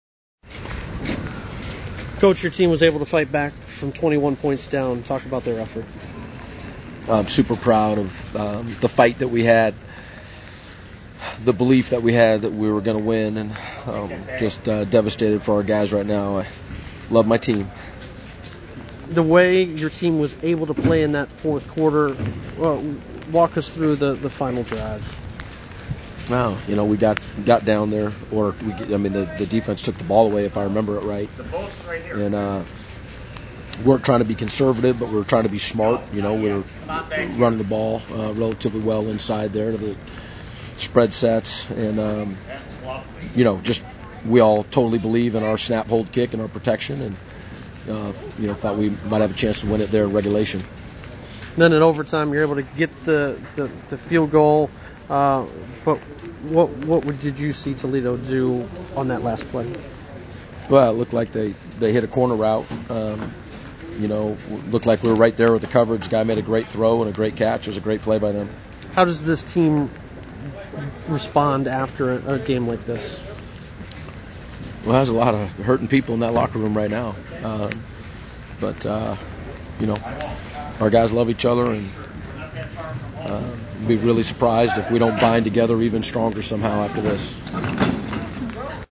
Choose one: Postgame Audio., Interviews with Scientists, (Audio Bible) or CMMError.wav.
Postgame Audio.